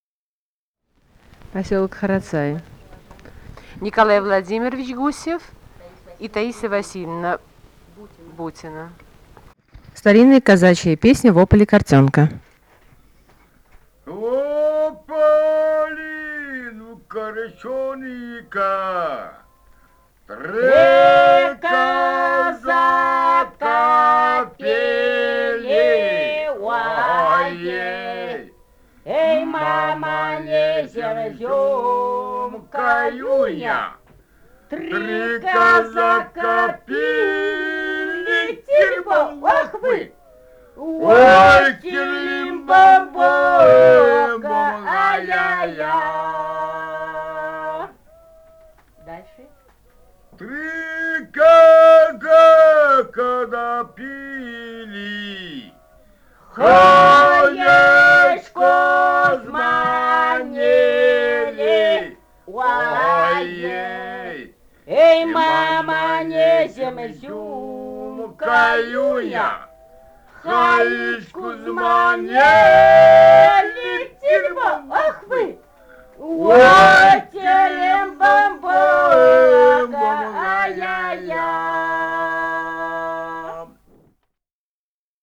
«Во поле корчонка» (лирическая «казачья»).
Бурятия, с. Харацай Закаменского района, 1966 г. И0905-01